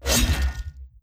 Melee Sword Sounds
Melee Weapon Attack 21.wav